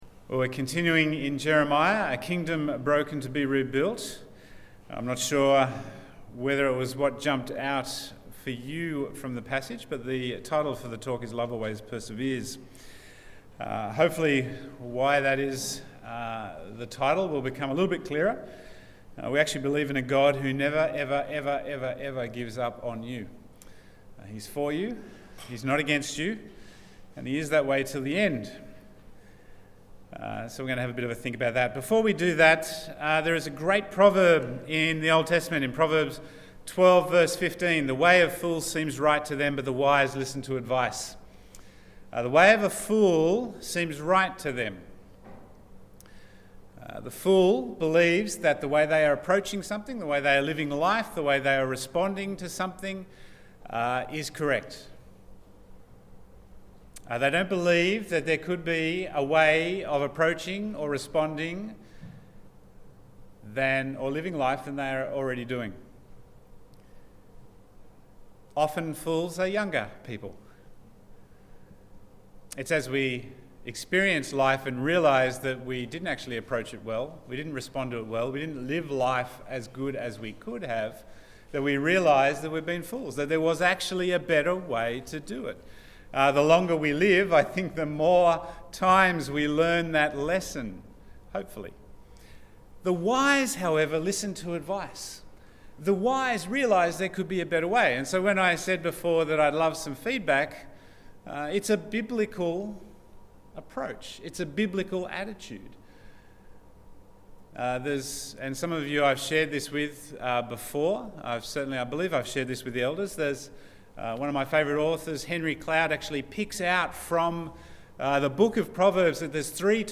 Bible Text: Jeremiah 36:1-26 | Preacher